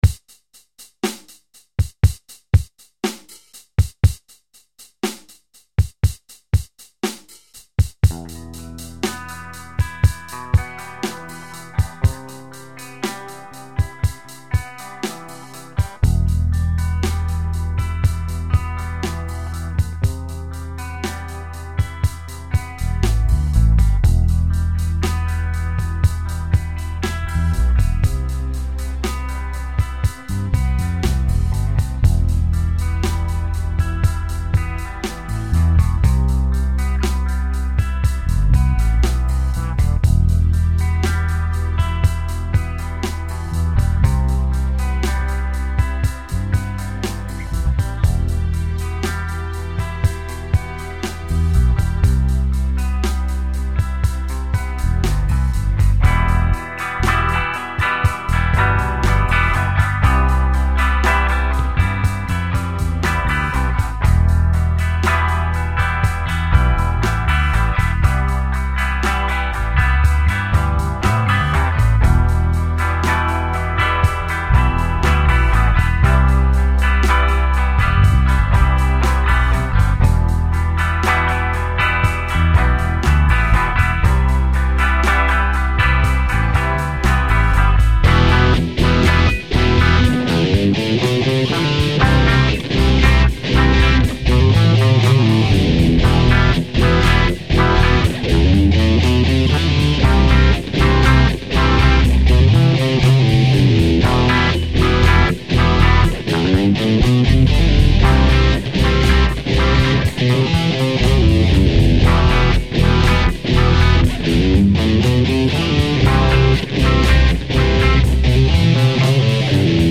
State Of Grace (multitrack demo).
Another song that should have lyrics, but I haven't got round to recording the vocals yet.